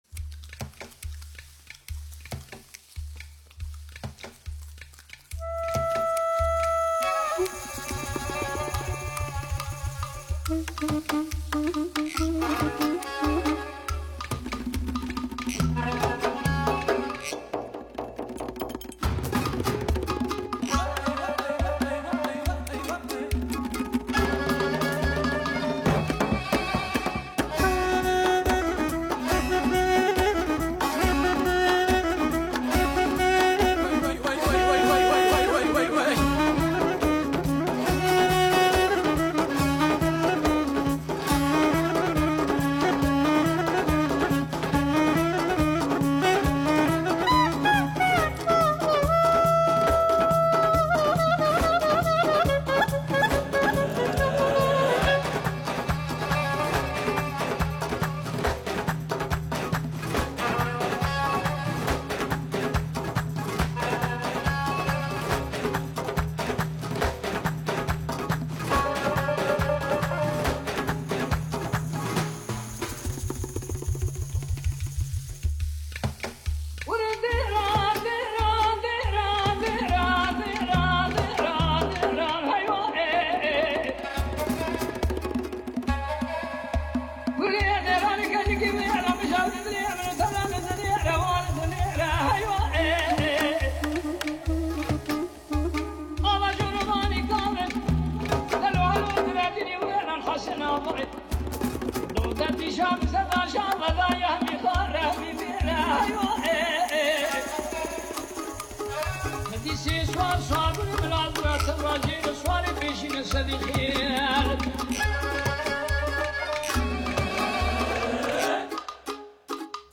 تیتراژ ابتدایی سریال (آواز کردی شروع)